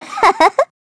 Yanne_L-Vox_Happy3_kr.wav